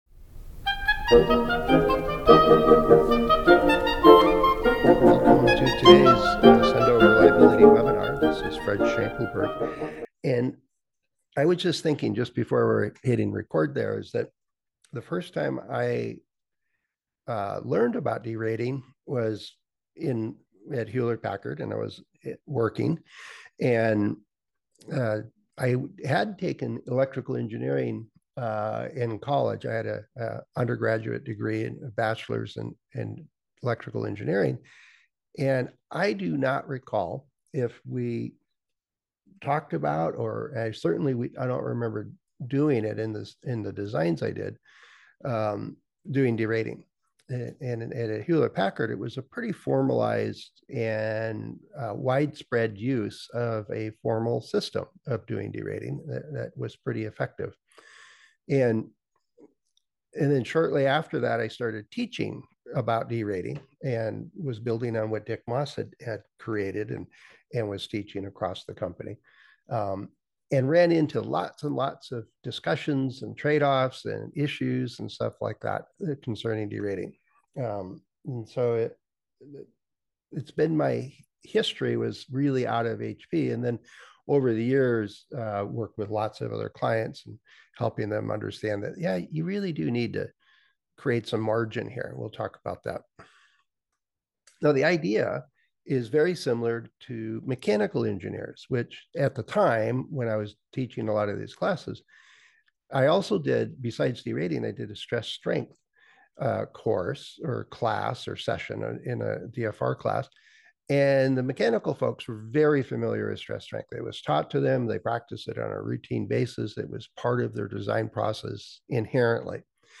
This Accendo Reliability webinar was originally broadcast on 13 May 2025.